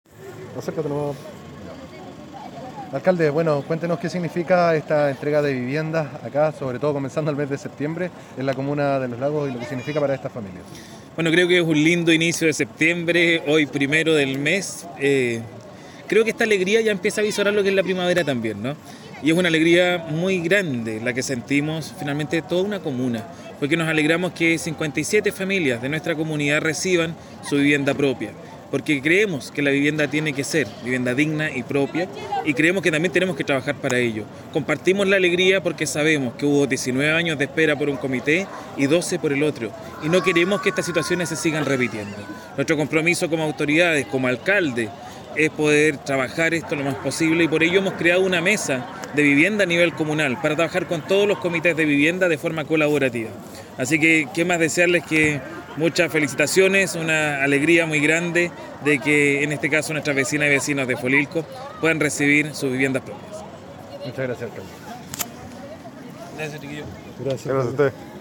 CUÑA ALCALDE RETAMAL